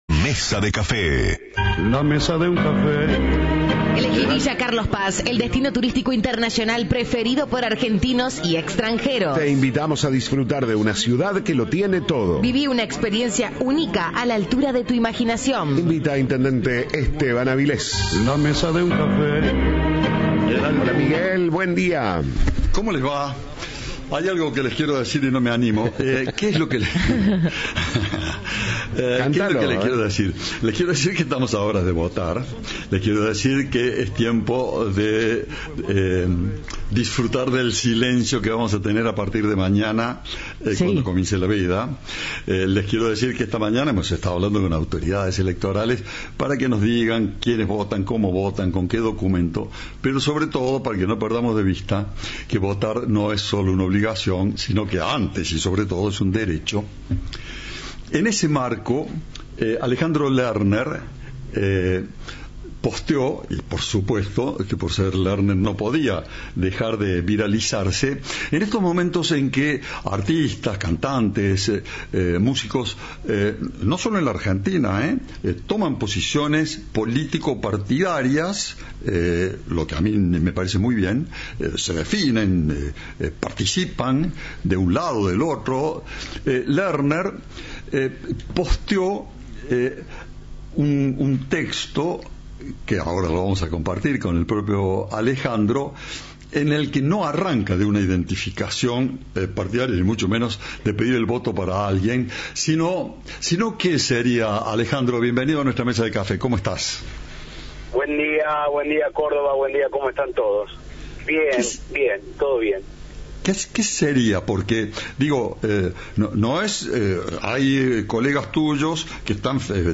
El cantante sostuvo que no podemos seguir viviendo con tanto miedo. En diálogo con Cadena 3, el artista advirtió que la sociedad naturalizó una realidad antinatural.